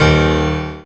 55y-pno10-a#3.wav